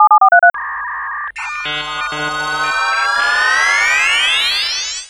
Modem Operation.wav